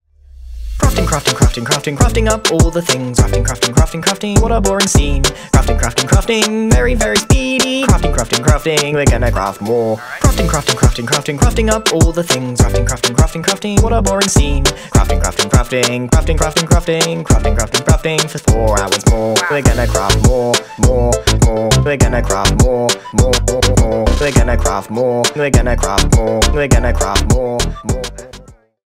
Ремикс
весёлые